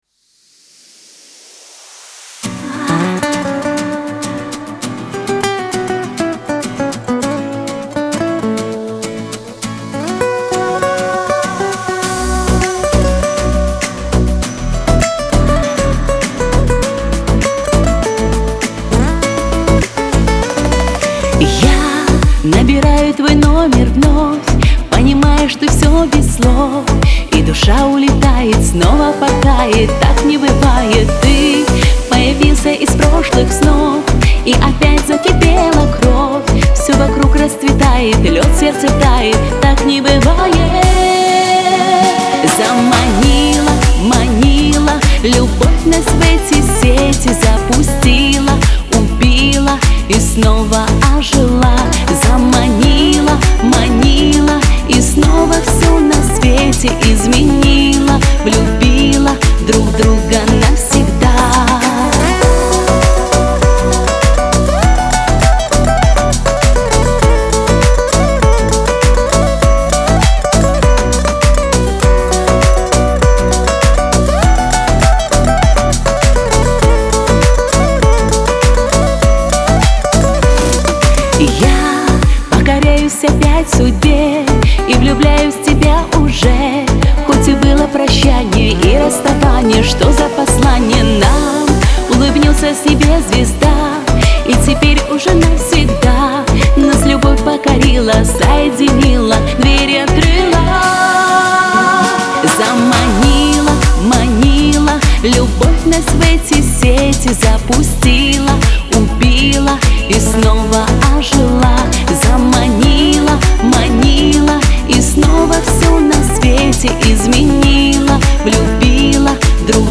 Веселая музыка
pop